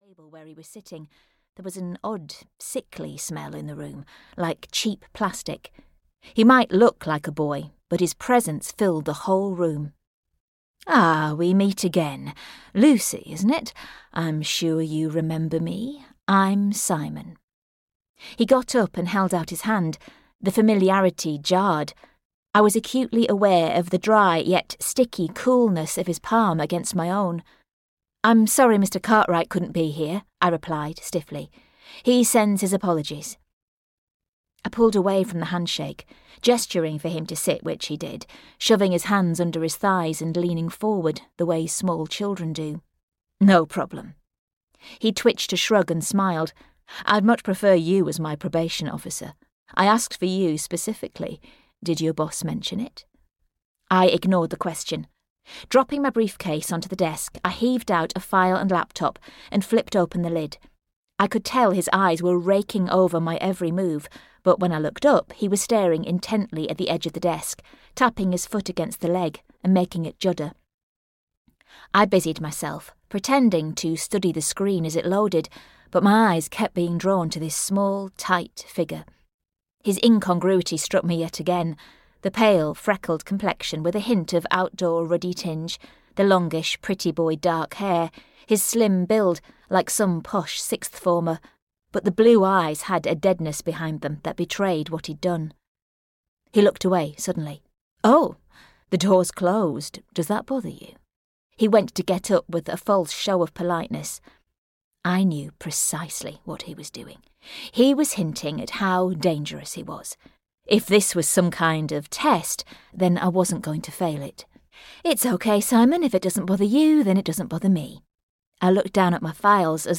The Man I Married (EN) audiokniha
Ukázka z knihy